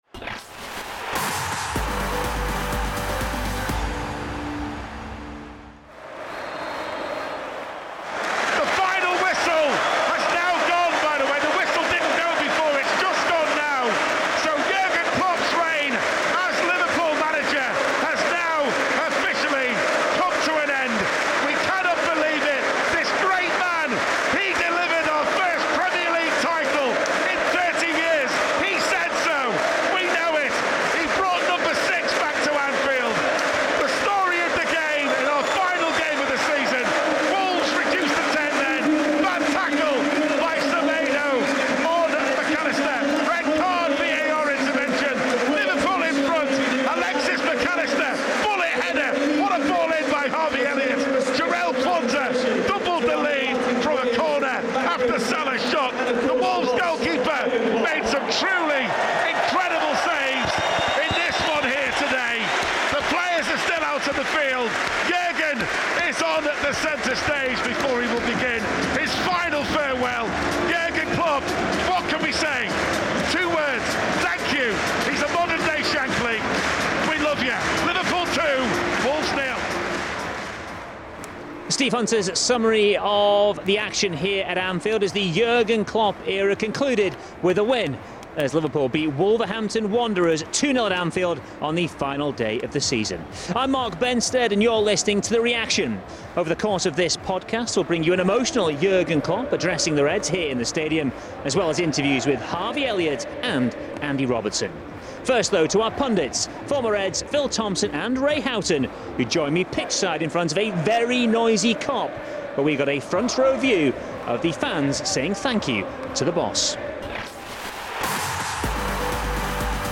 In this episode of The Reaction we bring you an emotional Jürgen Klopp addressing the LFC supporters as his unforgettable nine-year spell in charge of the Reds comes to an end. We hear from Harvey Elliott and Andy Robertson after the victory over Wolves as well as former Reds Phil Thompson and Ray Houghton who were pitch-side in front of the Kop where they got a front row view as the fans paid a fittingly noisy and fond farewell tribute to a legendary figure in the club’s history.